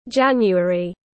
Tháng 1 tiếng anh gọi là january, phiên âm tiếng anh đọc là /ˈdʒæn.ju.ə.ri/
January /ˈdʒæn.ju.ə.ri/